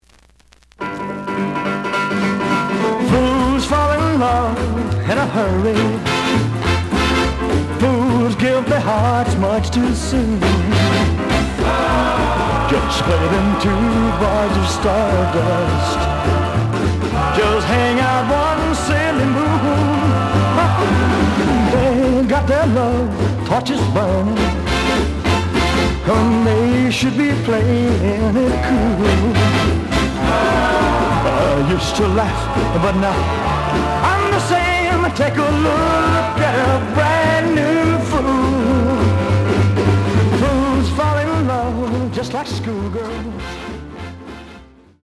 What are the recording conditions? The audio sample is recorded from the actual item. Some noise on both sides.